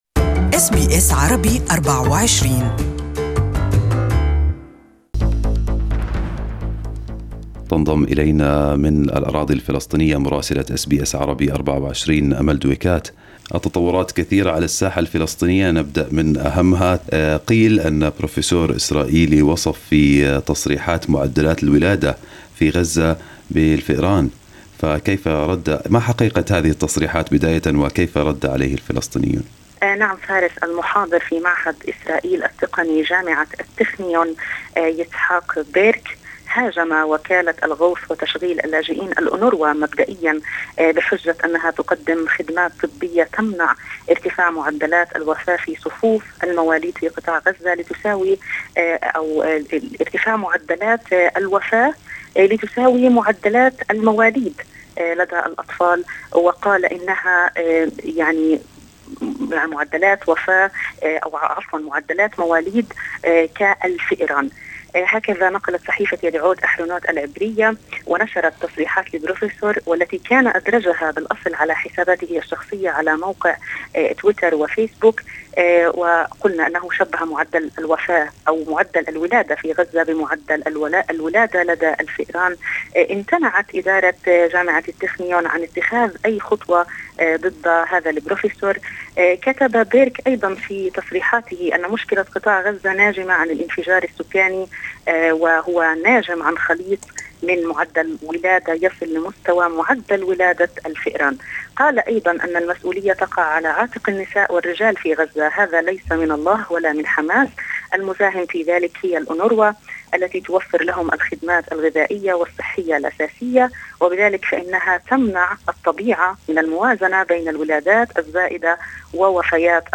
Our correspondent in Ramallah has the details